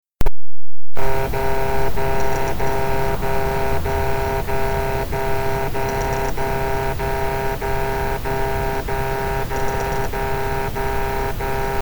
Voici le même bruit amplifié de 30 dB.
Bruit récurrent unité intérieure Mitsubishi PLFY P25 VFM E
On entend maintenant très bien ce son désagréable.
On dirait plus un petit moteur qui force, est-ce que les 4 volets bougent bien ?
J'entends en premier plan un ronflement entrecoupé de silence et d'origine plutôt "secteur électrique" avec en arrière plan des tac-tac-tac discrets, style prise d'origine (butée mécanique) d'un moteur pas à pas (comme celui d'un volet).